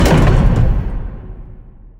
DockingAirlock.wav